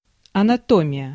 Ääntäminen
Ääntäminen Tuntematon aksentti: IPA: /ənɐˈtomʲɪjə/ IPA: /a.na.ˈto.mi.ʲa/ Haettu sana löytyi näillä lähdekielillä: venäjä Käännös Ääninäyte Substantiivit 1. anatomy US Translitterointi: anatomija.